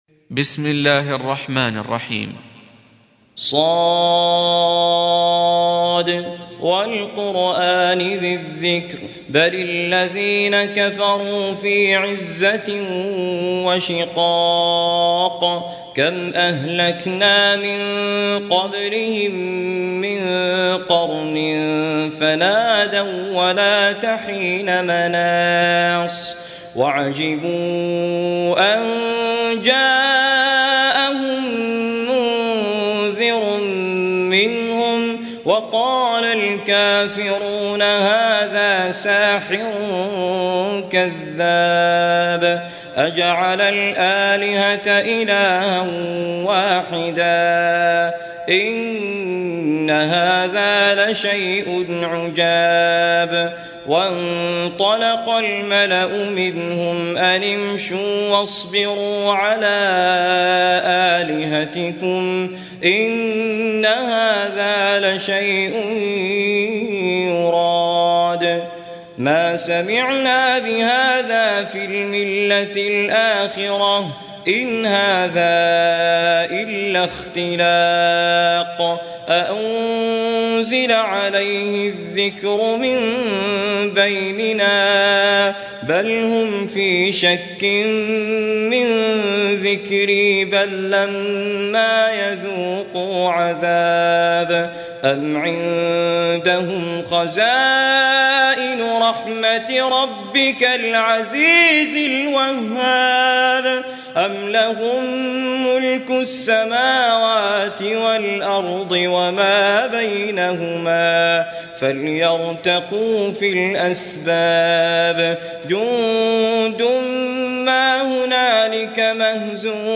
38. Surah S�d. سورة ص Audio Quran Tarteel Recitation
Surah Repeating تكرار السورة Download Surah حمّل السورة Reciting Murattalah Audio for 38.